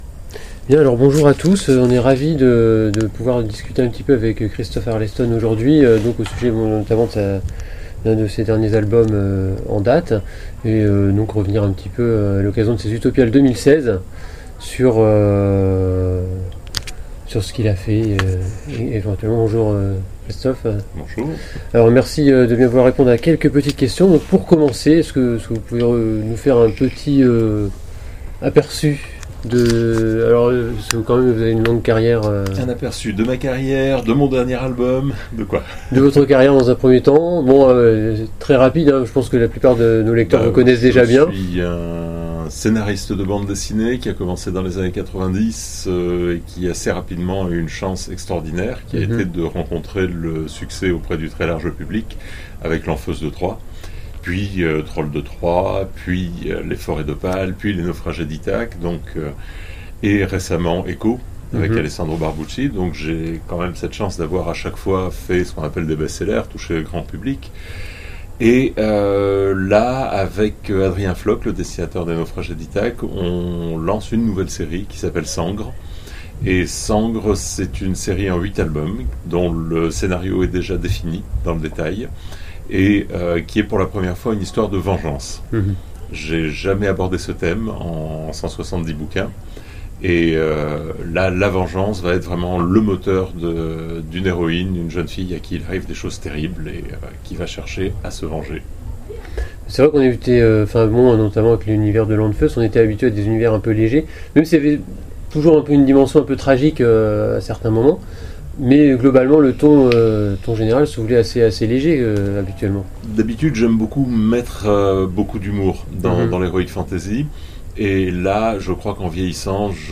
Interview de Christophe Arleston aux Utopiales 2016